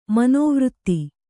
♪ manōvřtti